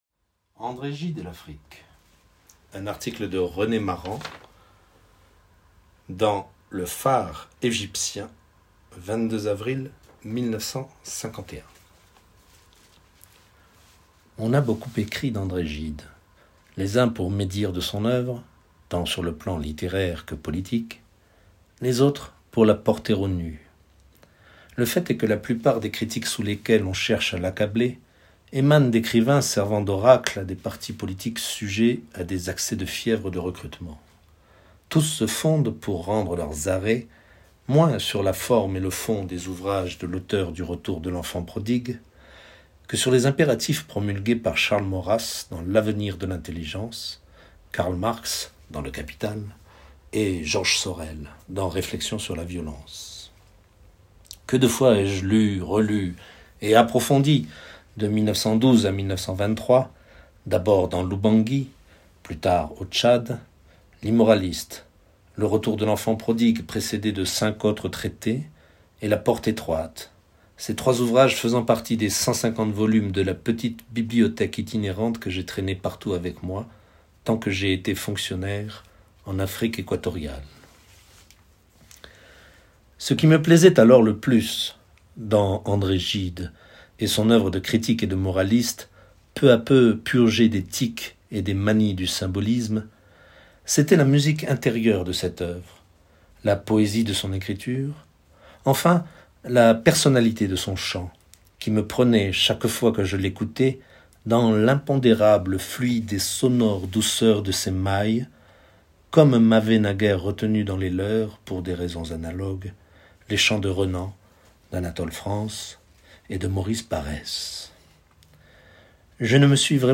En 1951, René Maran rend hommage à André Gide dans un article paru dans Le Coin littéraire, “André Gide et l'Afrique”. L'écrivain Michaël Ferrier, en visite aux Archives André Gide en août 2023, a lu pour nous ce texte.
Michaël Ferrier lecture de René Maran.mp3